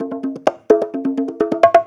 Conga Loop 128 BPM (1).wav